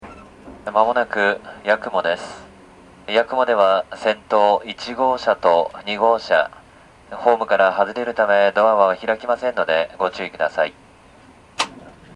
しばらくすると八雲到着の車内放送は